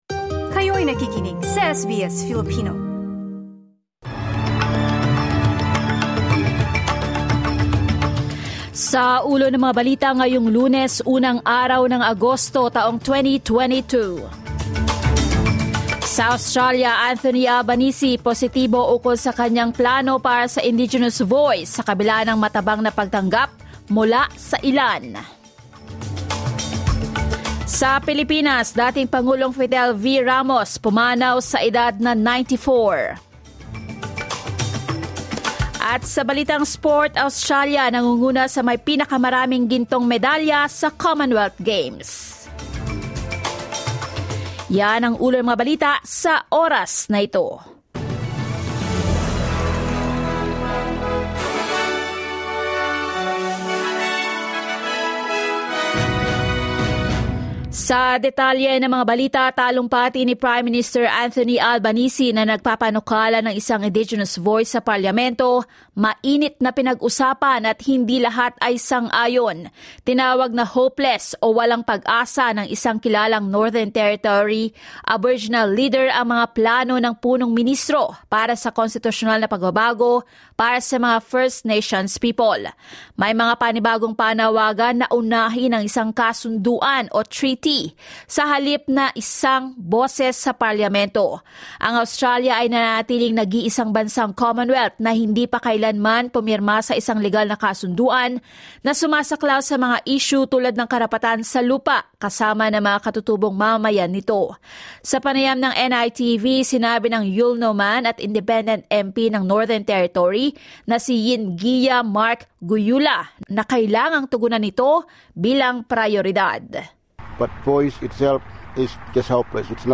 SBS News in Filipino, Monday 1 August